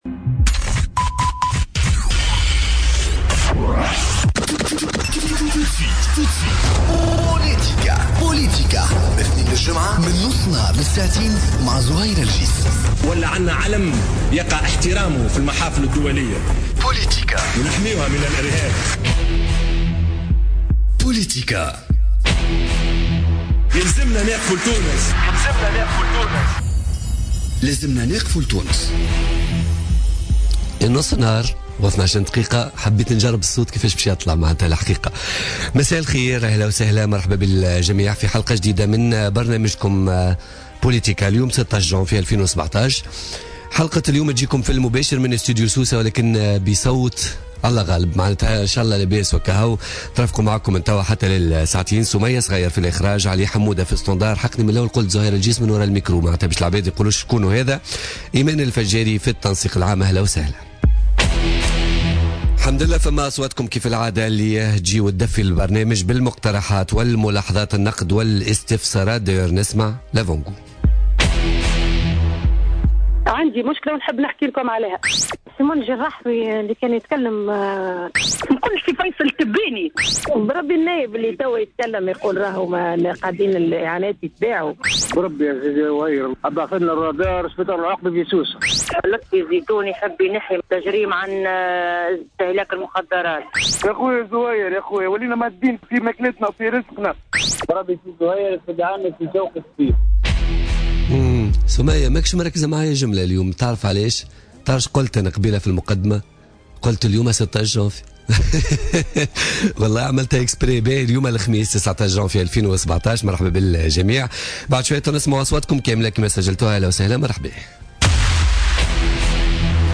Zied Laâdheri ministre de l'Industrie et du Commerce, invité de politica